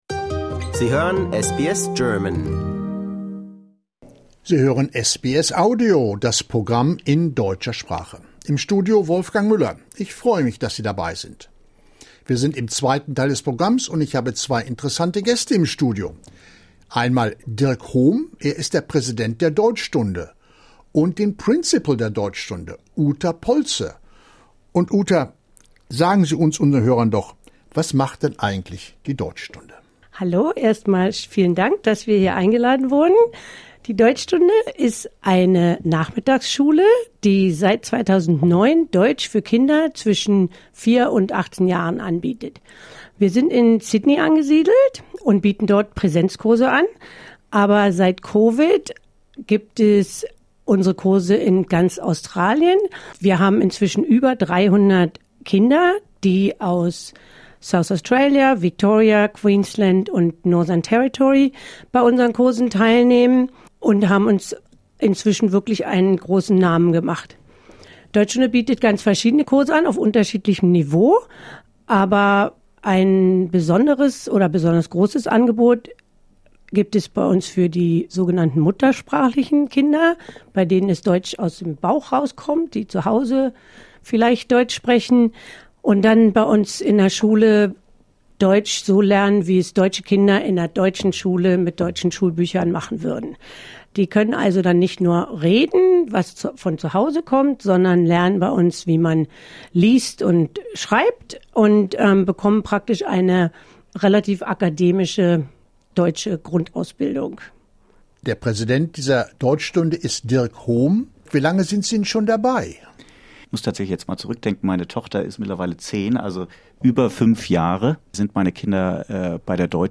Im Studio von SBS Audio